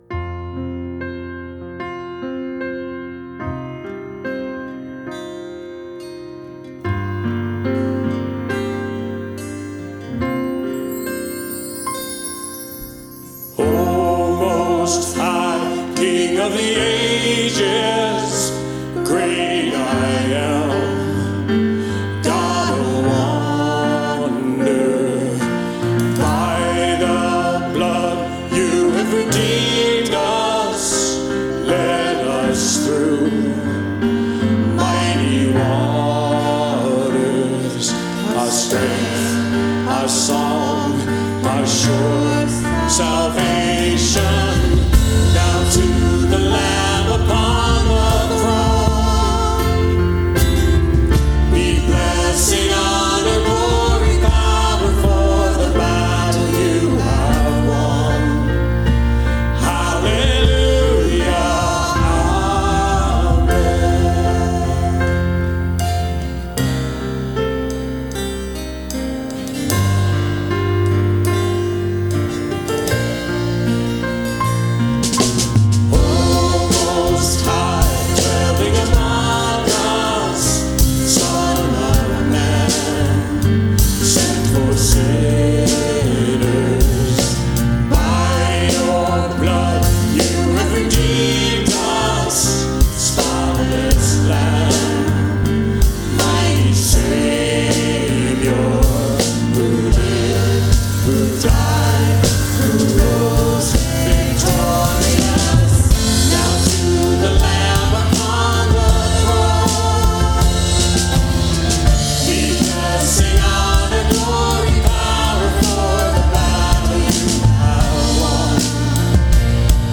New Congregational Song